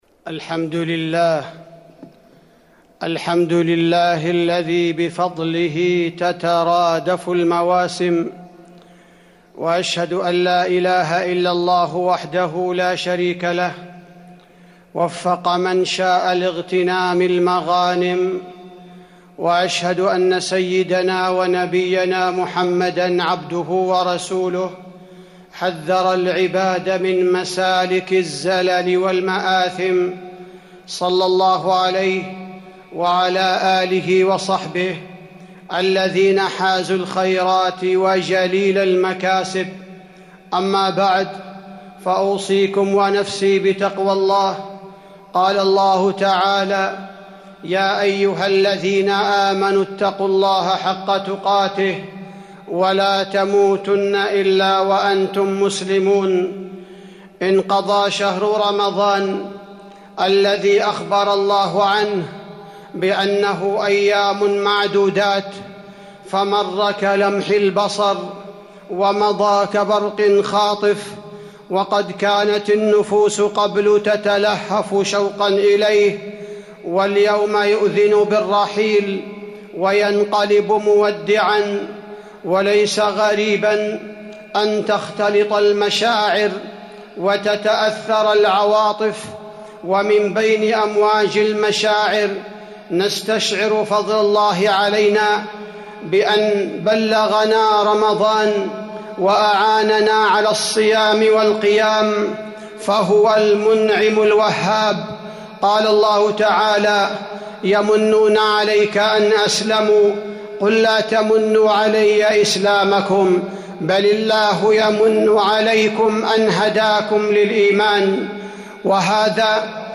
تاريخ النشر ٢٩ رمضان ١٤٤١ هـ المكان: المسجد النبوي الشيخ: فضيلة الشيخ عبدالباري الثبيتي فضيلة الشيخ عبدالباري الثبيتي وداع رمضان